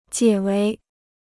解围 (jiě wéi): to lift a siege; to help sb out of trouble or embarrassment.